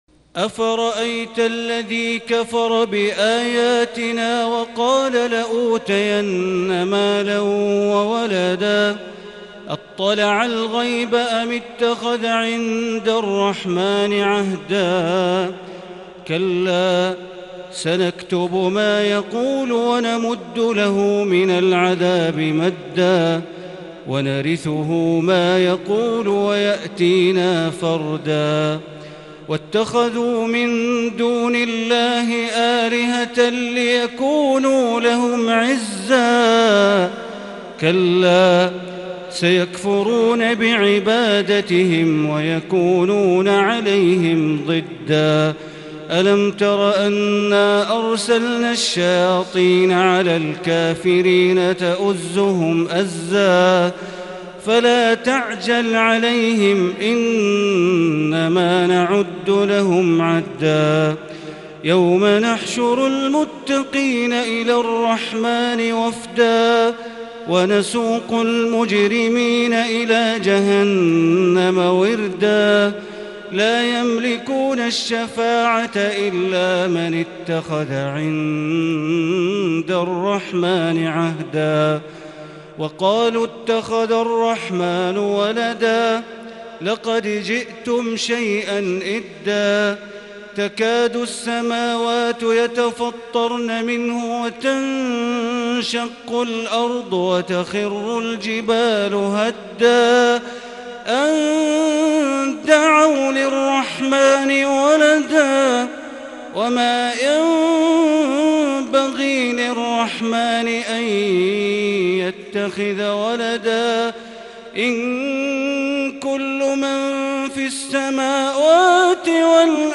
تراويح ليلة 21 رمضان 1441هـ من سورتي مريم (77-98) و طه (1-82) Taraweeh 21 th night Ramadan 1441H Surah Maryam and Taa-Haa > تراويح الحرم المكي عام 1441 🕋 > التراويح - تلاوات الحرمين